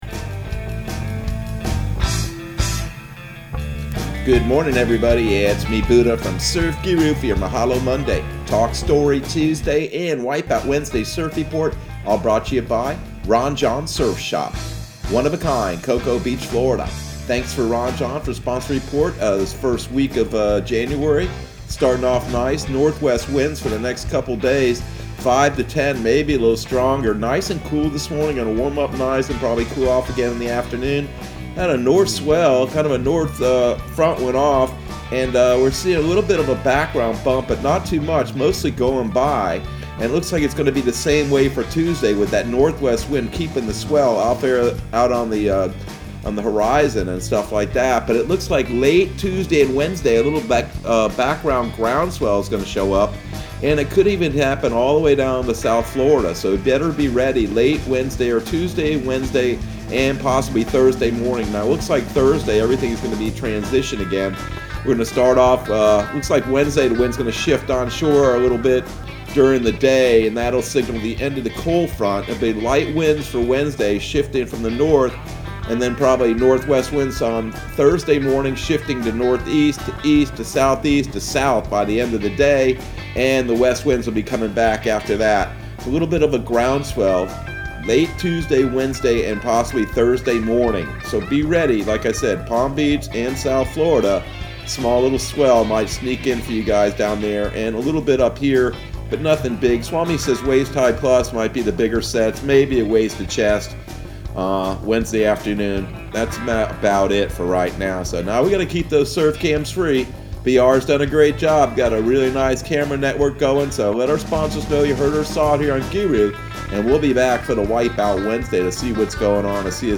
Surf Guru Surf Report and Forecast 01/04/2021 Audio surf report and surf forecast on January 04 for Central Florida and the Southeast.